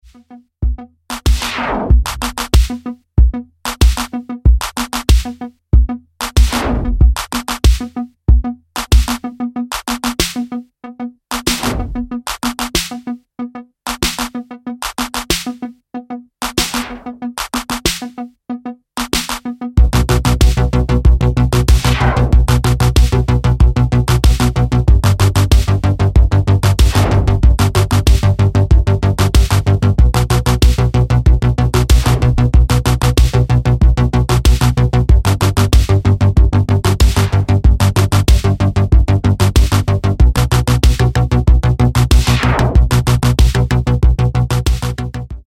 dark italo / electro techno moderne